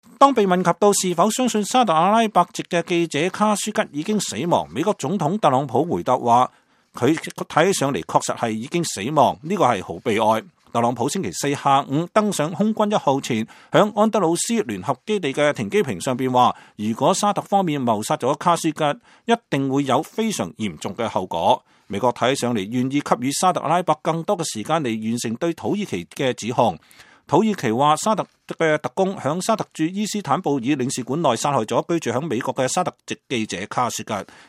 美國國務卿蓬佩奧在白宮與特朗普總統會面後與媒體談他的沙特阿拉伯之行。